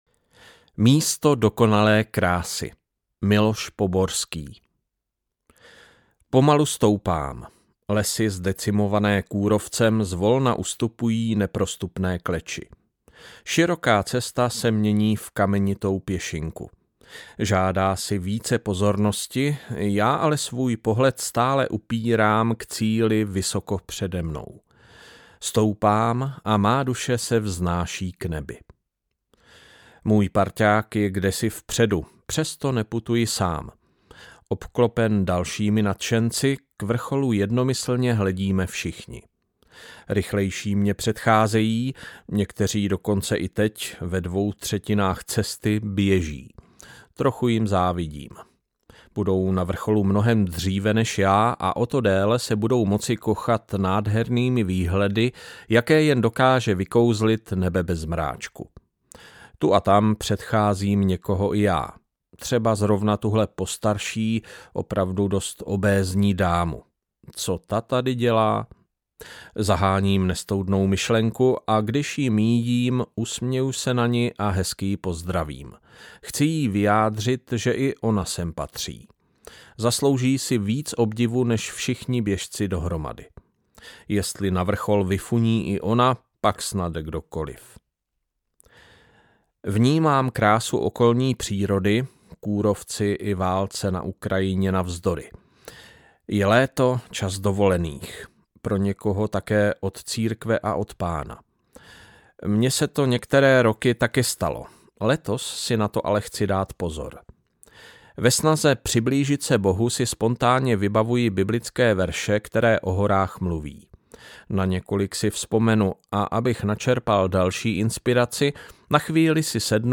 Nahráno ve studiu Rádia 7.
Vyberte Audiokniha 79 Kč Další informace